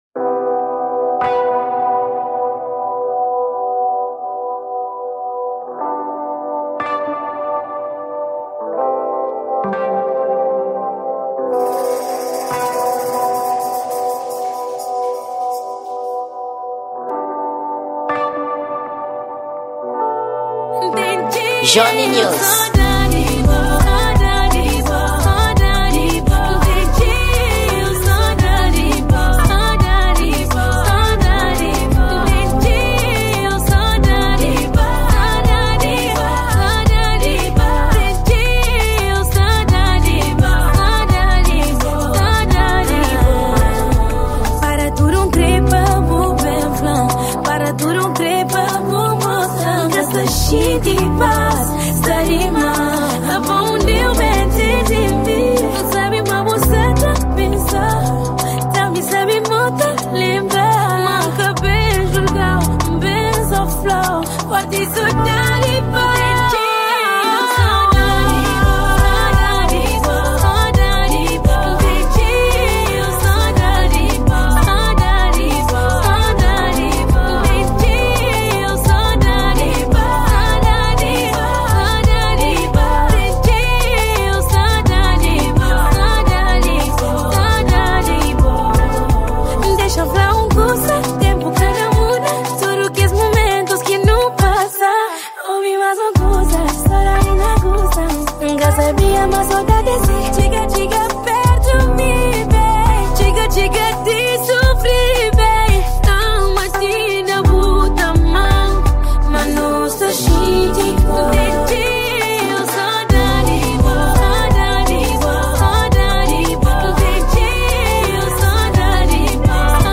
Gênero: Kizomba